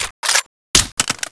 New reload sounds included
hmg_clipout.wav